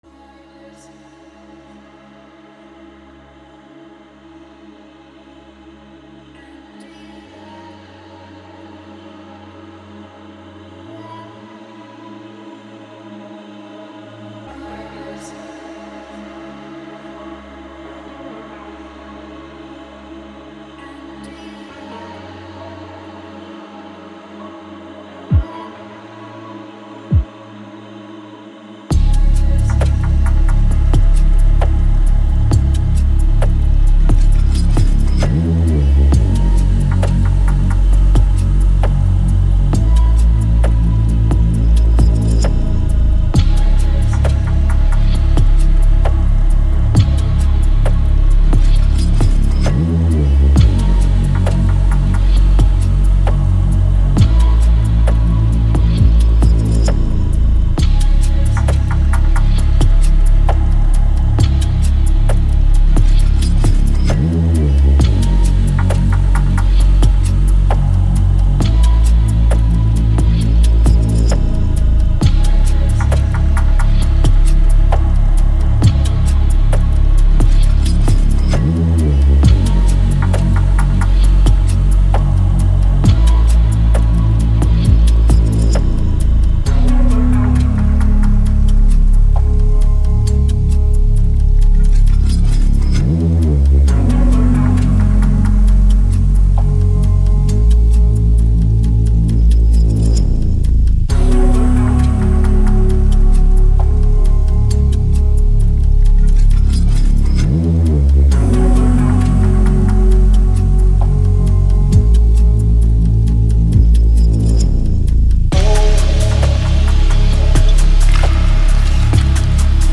Genre Garage